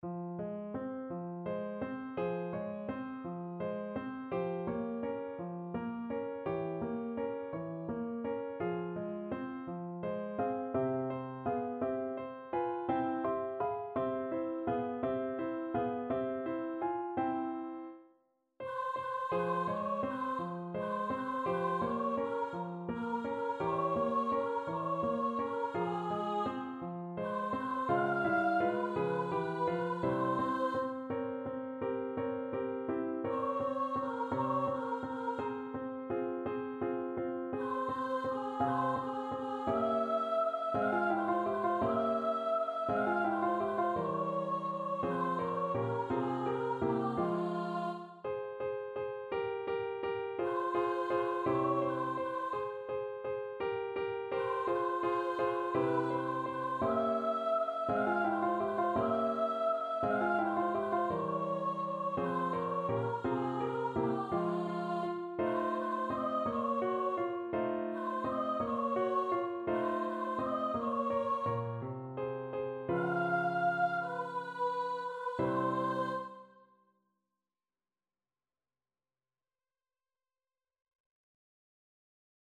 . = 56 Andante
6/8 (View more 6/8 Music)
Classical (View more Classical Soprano Voice Music)